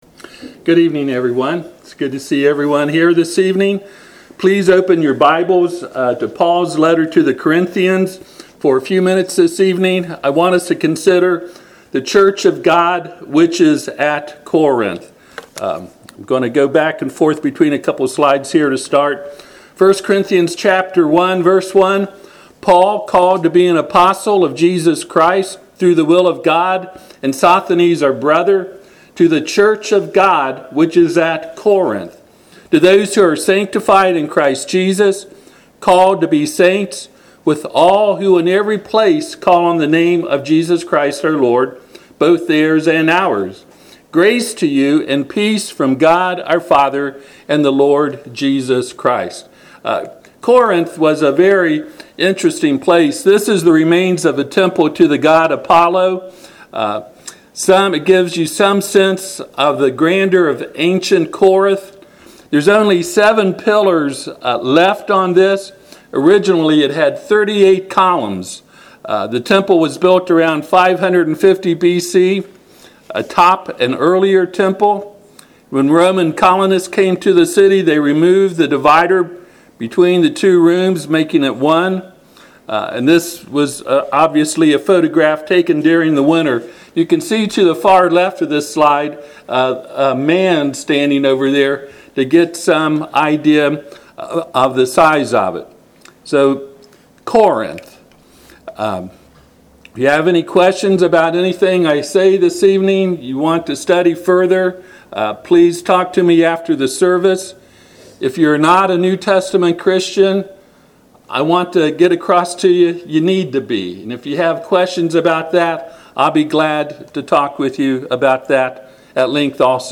Passage: 1 Corinthians 1:1-3 Service Type: Sunday PM